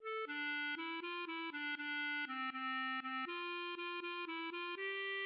\set Staff.midiInstrument="acoustic guitar (nylon)"
\key g \major
\tempo 4=120
\set Staff.midiInstrument="clarinet"